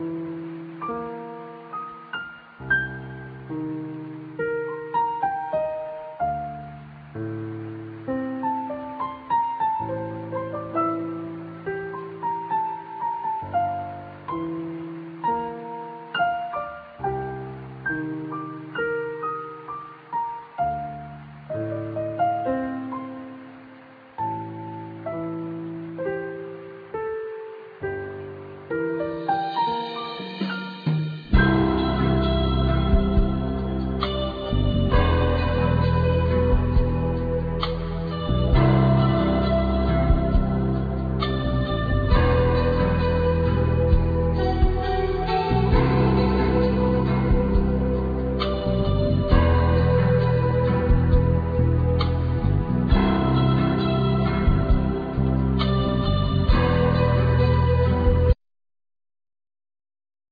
Harp
Bamboo Flute